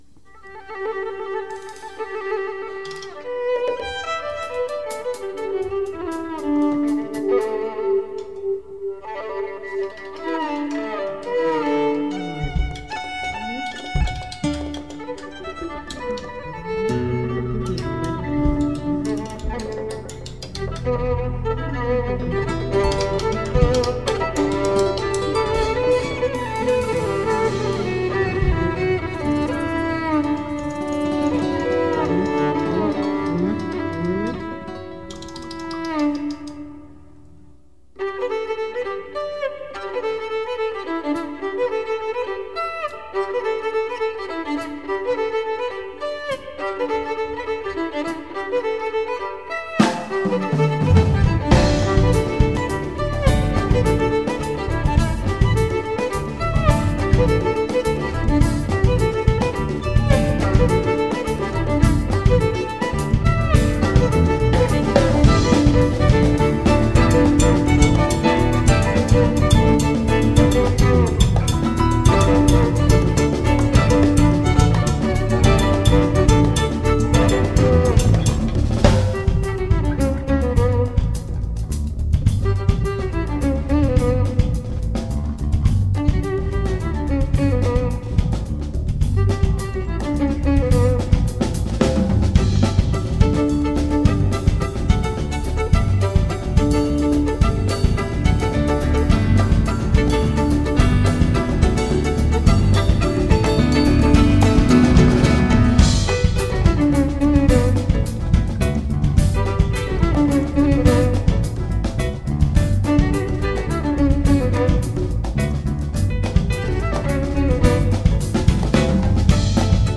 ■ ライヴ音源
※ステレオマイク一本での録音ですので音質はあまりよくありません。ご了承ください
'06/03/12　西宮・鳴尾公民館